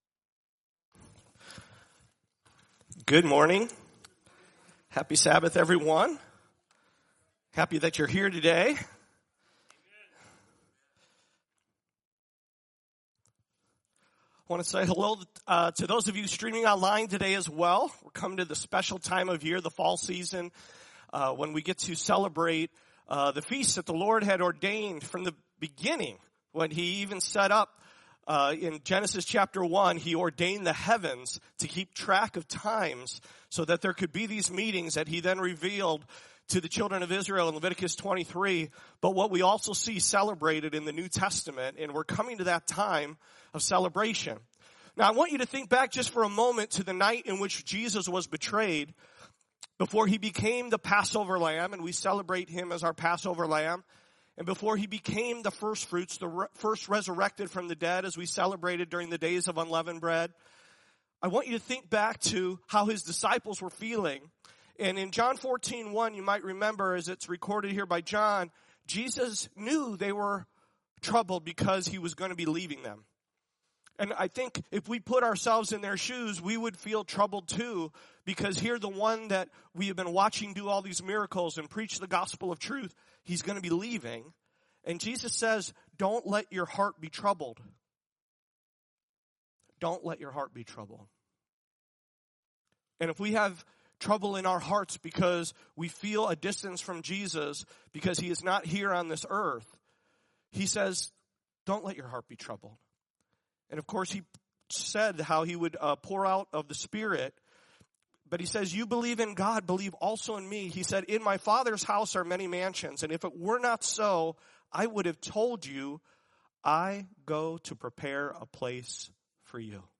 The Gospel of Jesus You Haven’t Heard - Sabbath Christian Church | Rock Valley Christian Church